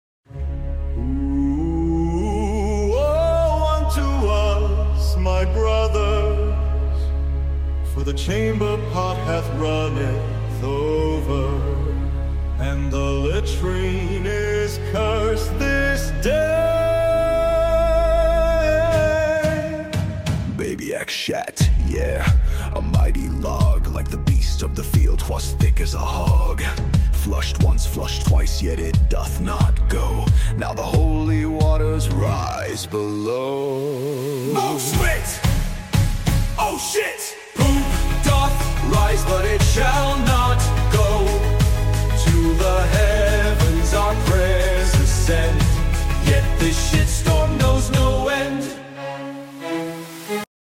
AI Gregorian Chant